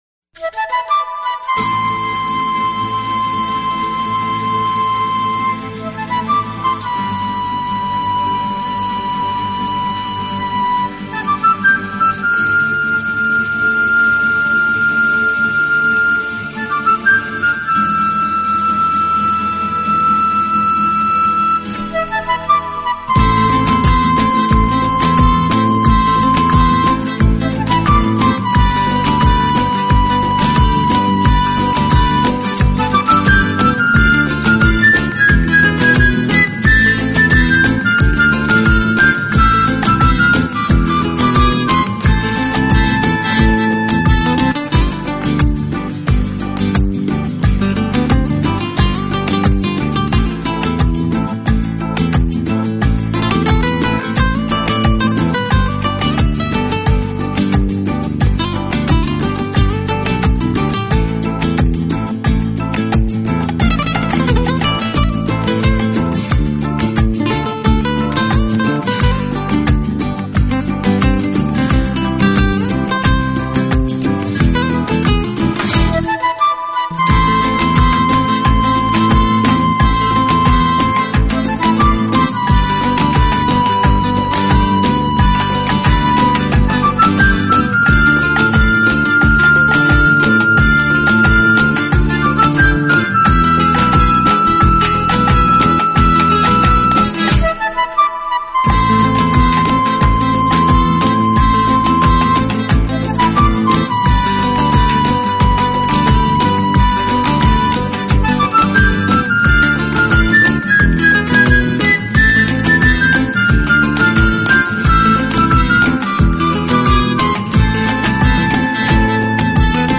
【吉他专辑】